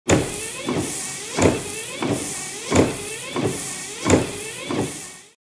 جلوه های صوتی
دانلود صدای شیشه پاک کن 3 از ساعد نیوز با لینک مستقیم و کیفیت بالا
برچسب: دانلود آهنگ های افکت صوتی حمل و نقل دانلود آلبوم صدای شیشه پاک کن ماشین از افکت صوتی حمل و نقل